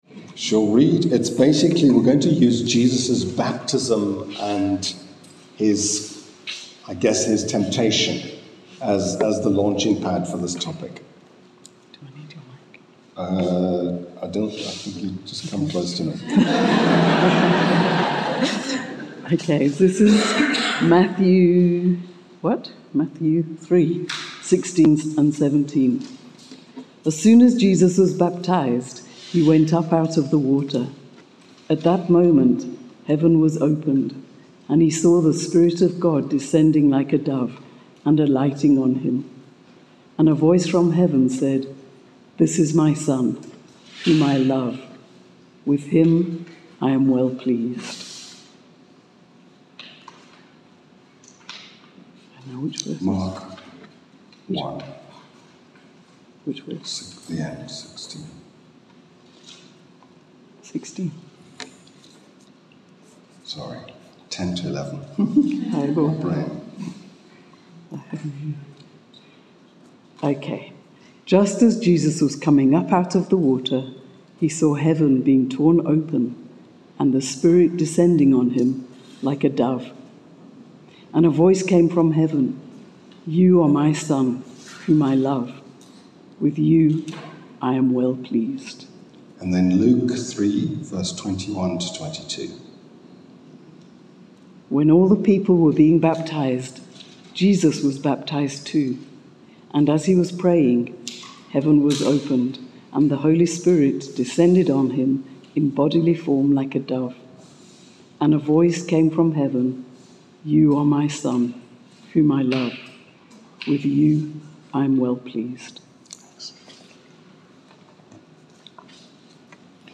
From Hillside Vineyard Christian Fellowship, at Aan-Die-Berg Gemeente.